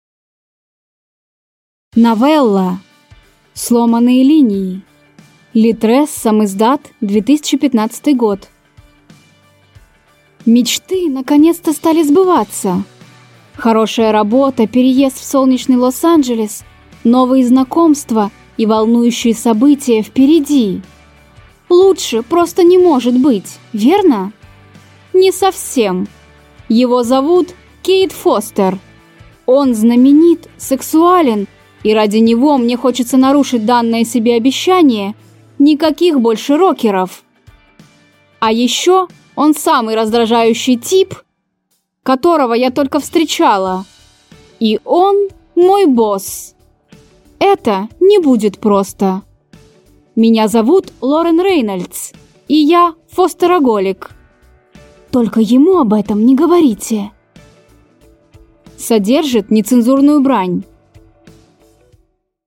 Аудиокнига Сломанные линии | Библиотека аудиокниг